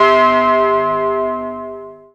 MORLOCKS C4.wav